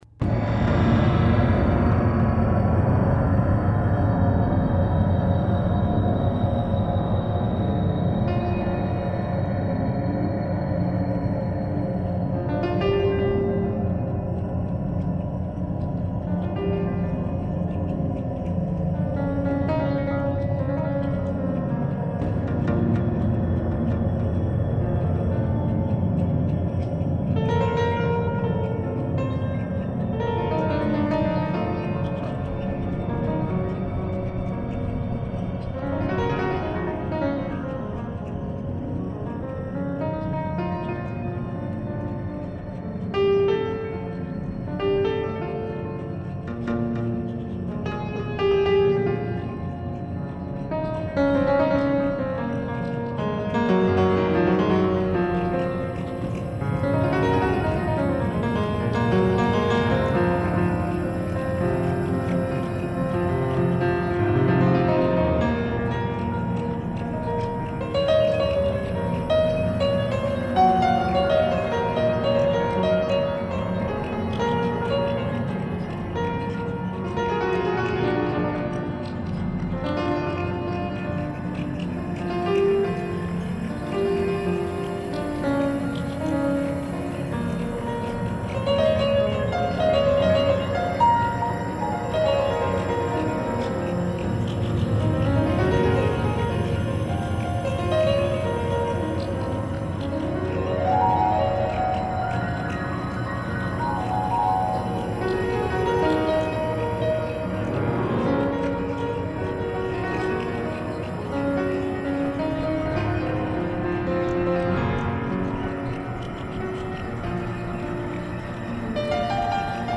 récital de piano augmenté